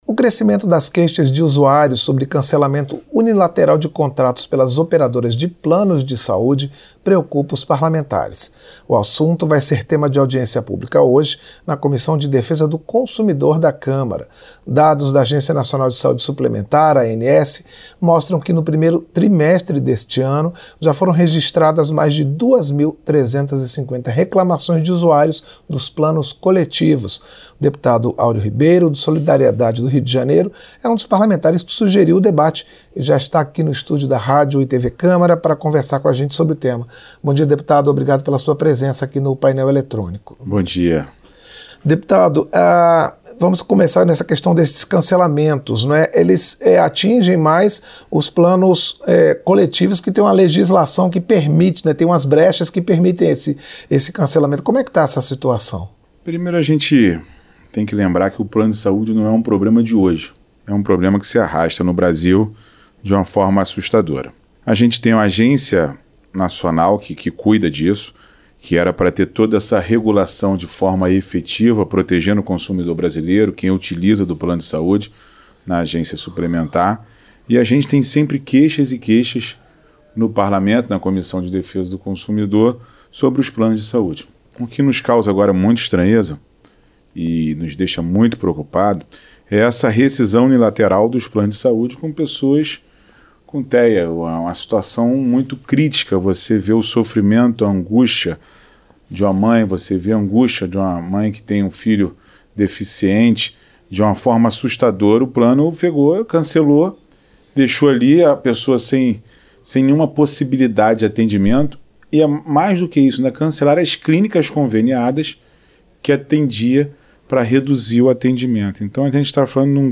Entrevista - Dep. Aureo Ribeiro (Solidariedade-RJ)